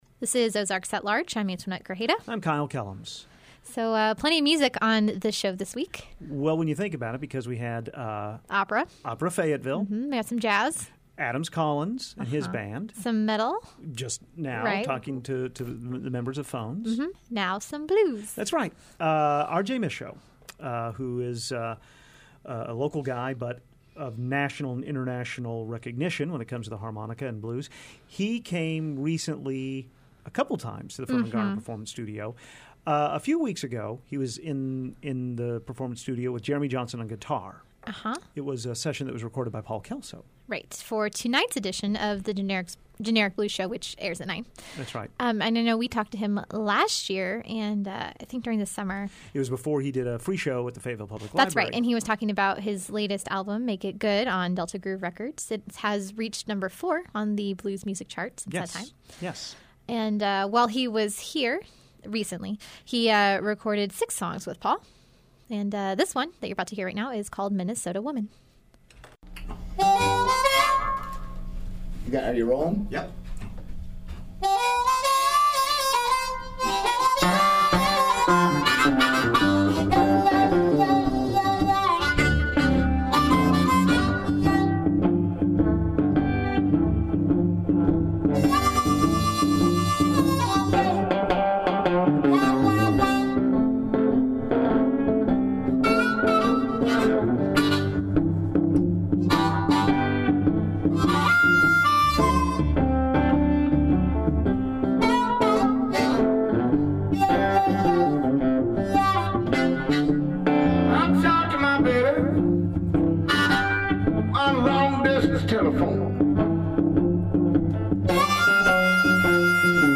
Live Blues From Firmin-Garner Performance Studio